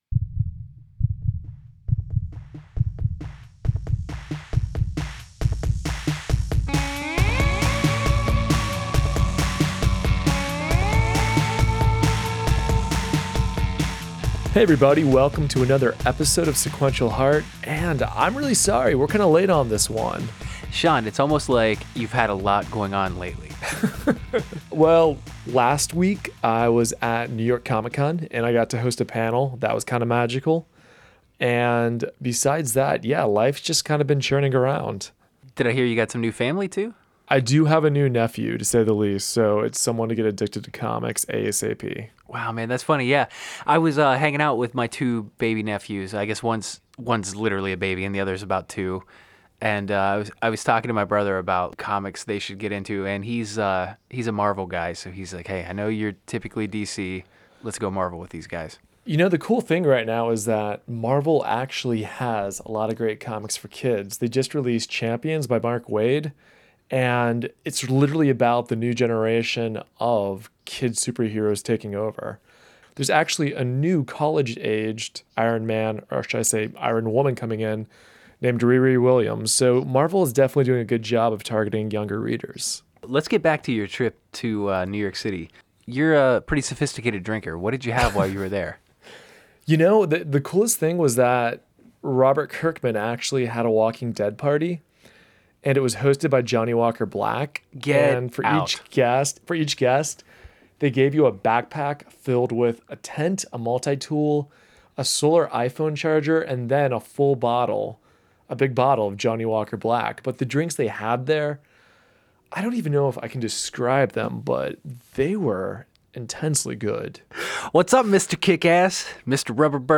on the bustling floor of New York Comic Con